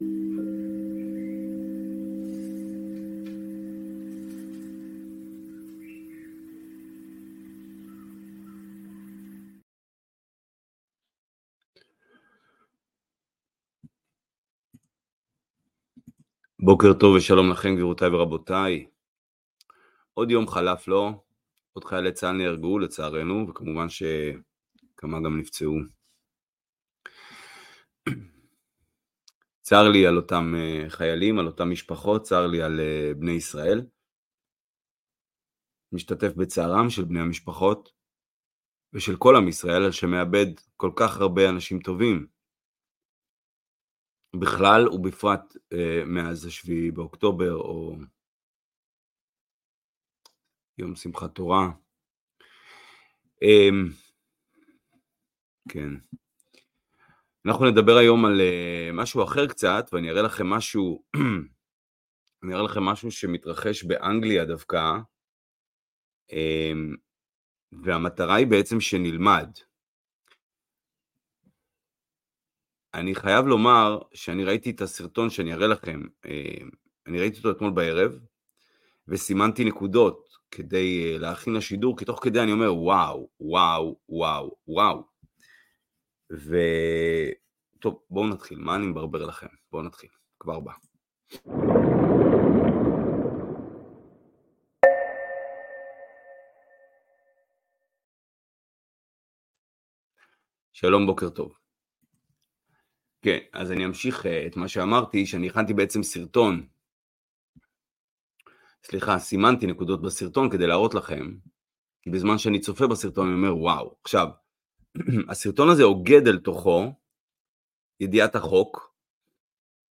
שיעור בחוק, ריבונות וכוחה של קהילה on 16-May-24